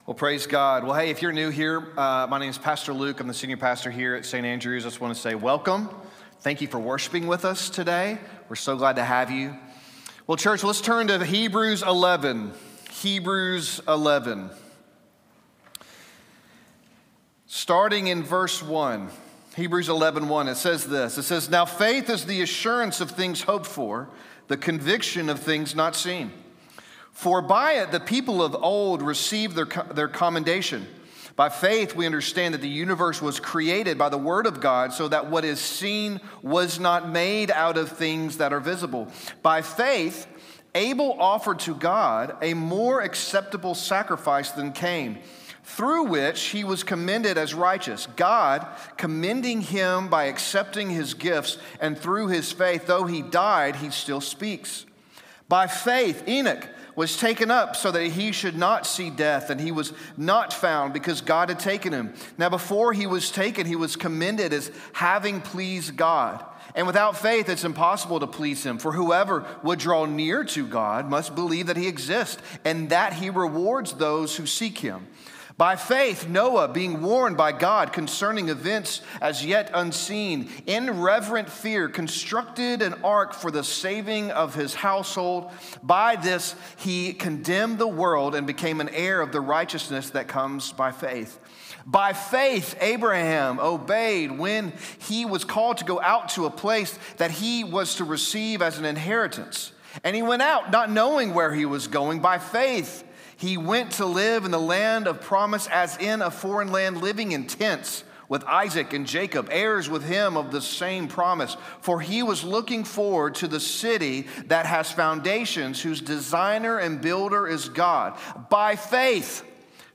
Sermon Archive